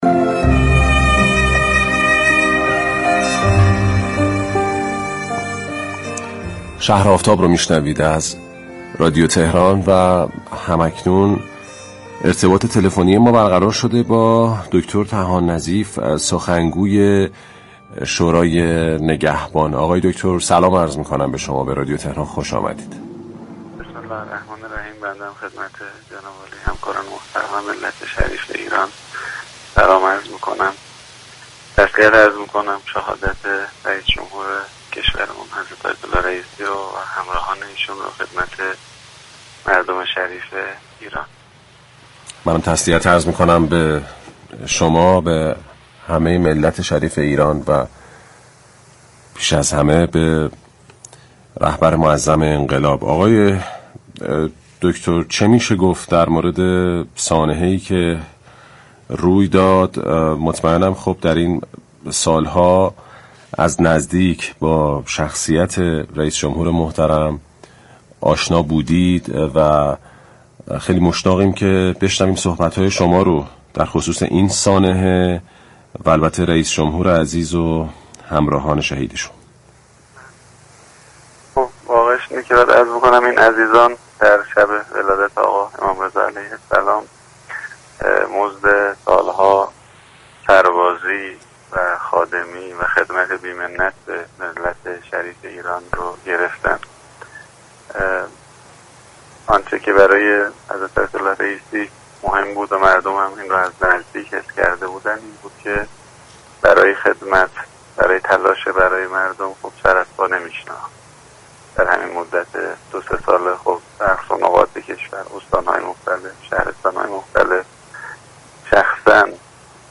به گزارش پایگاه اطلاع رسانی رادیو تهران، هادی طحان نظیف سخنگوی شورای نگهبان در گفتگو با برنامه «شهر آفتاب» 31 اردیبهشت ضمن عرض تسلیت شهادت سید ابراهیم رئیسی رئیس جمهور و جمعی از همراهان ایشان گفت: این عزیزان در شب ولادت امام رضا (ع) مزد سالها سربازی، خادمی و خدمت بی‌منت به ملت شریف ایران را دریافت كردند.